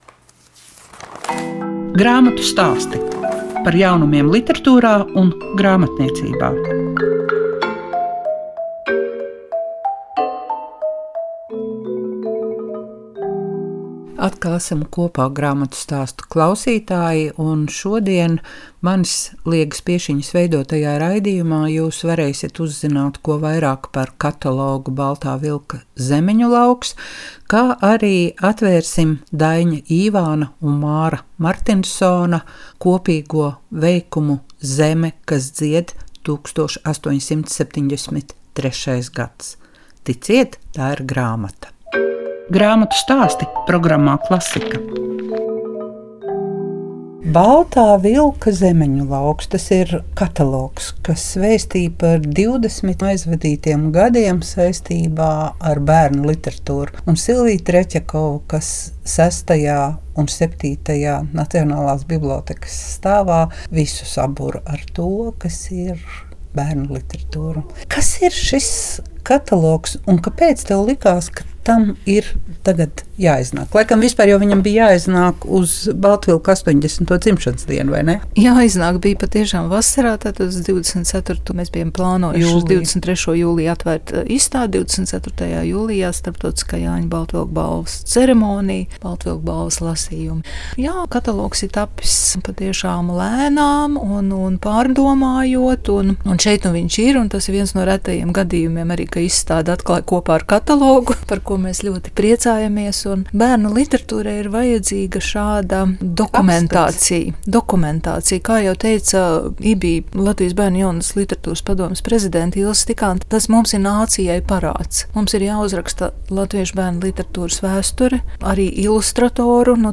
Un vēl pārraidē skan intervija